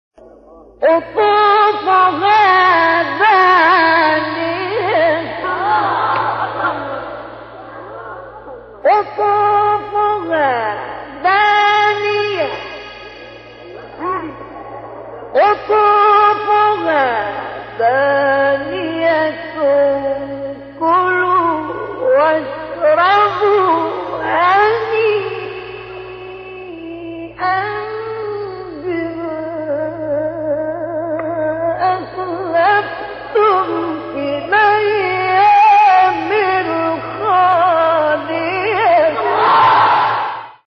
فرازی زیبا از تلاوت قرآن